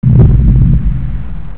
Jurassic Park: T-Rex step - closer